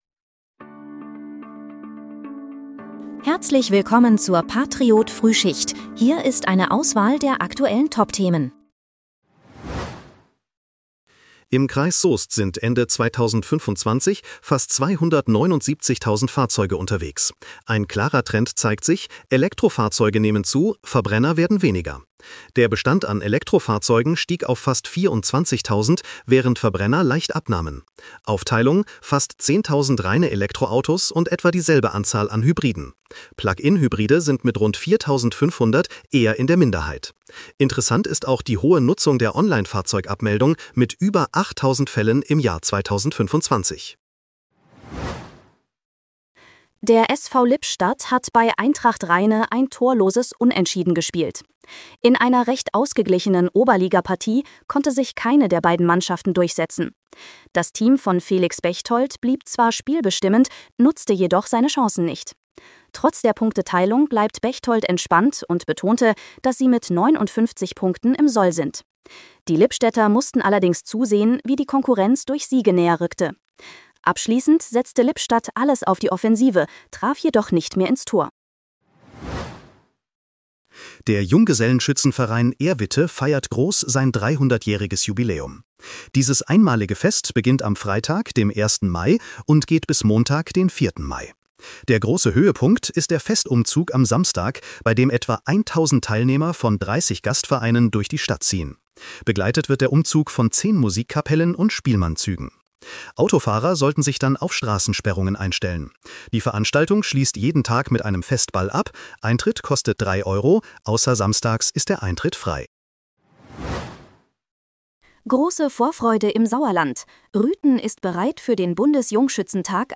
Dein morgendliches News-Update
mit Hilfe von Künstlicher Intelligenz.